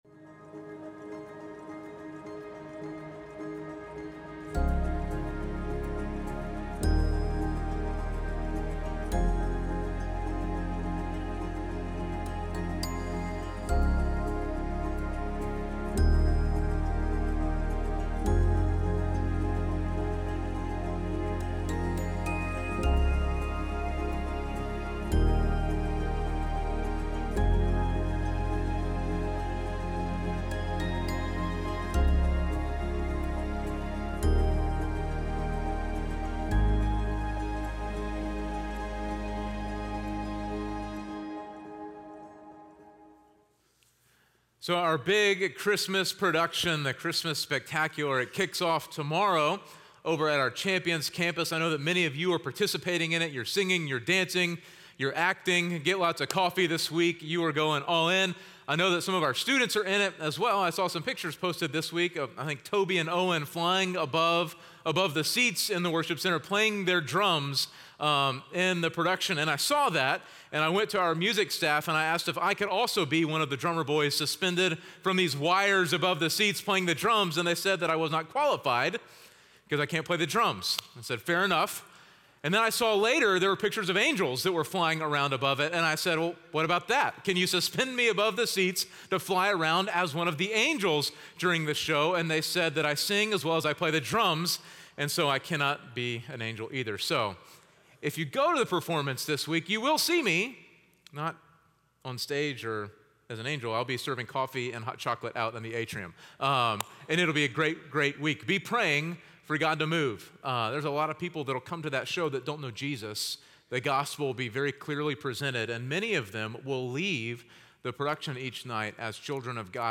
North Klein Sermons – Media Player